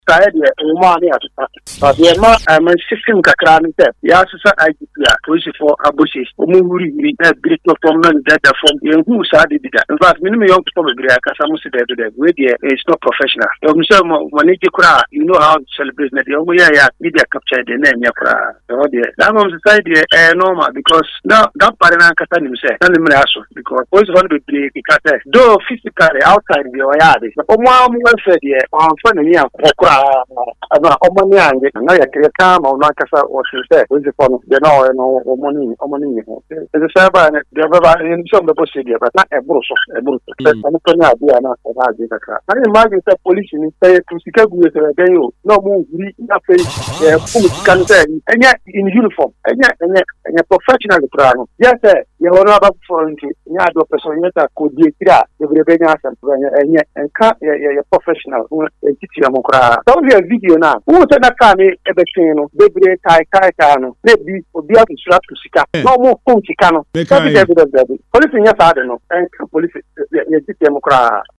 Speaking in a phone interview with Hot FM Morning Drive